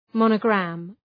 Προφορά
{‘mɒnə,græm}